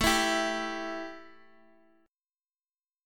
A 5th 7th